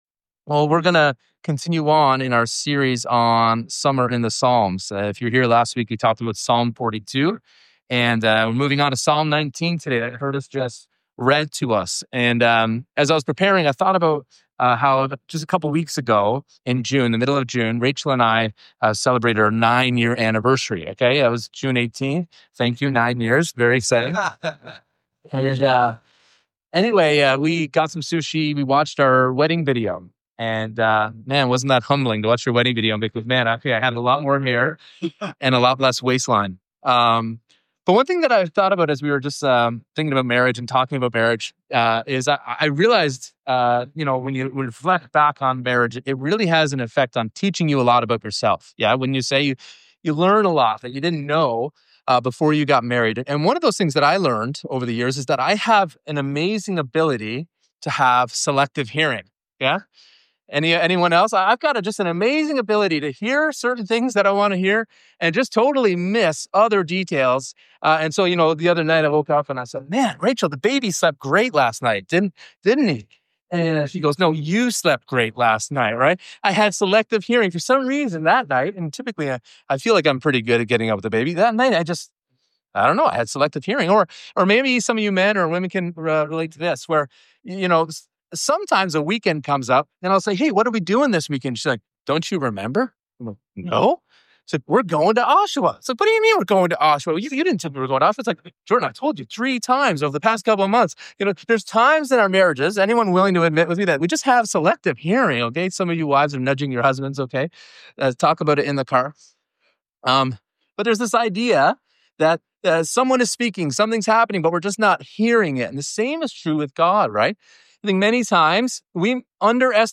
The sermon concludes by pointing to Jesus as God's ultimate and final revelation, the One who makes us truly blameless before God.